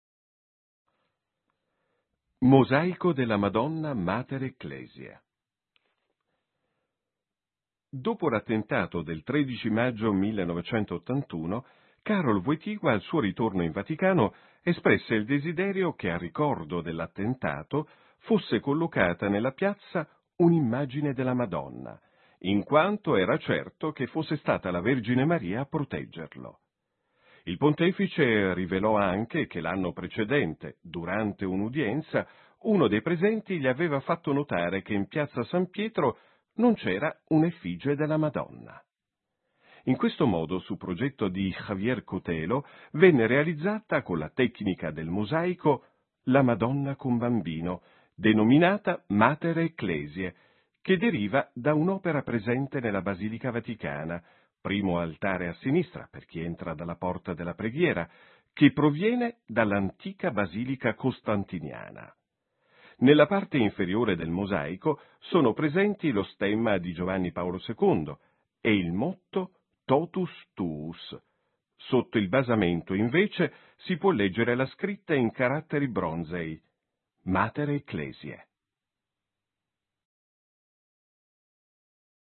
Testo del frate